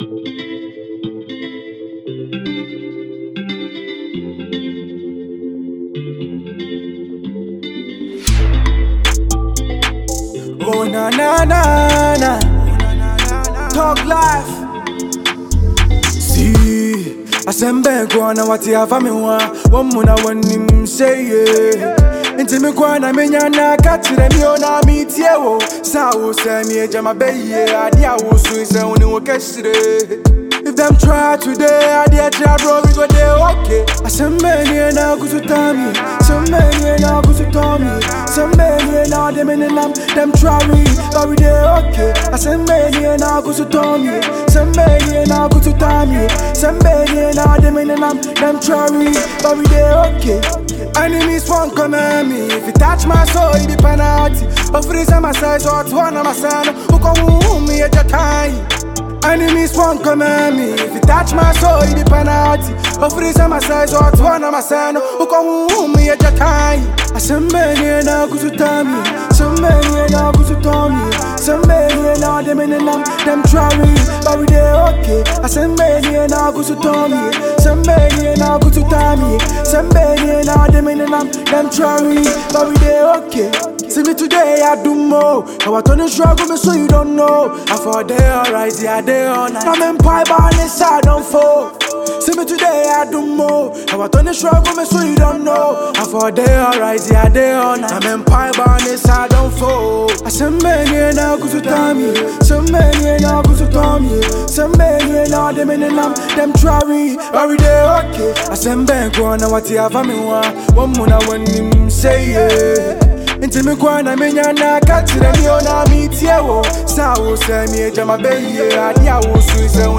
With his signature flow and fearless delivery
streetwise, unfiltered, and ready to dominate playlists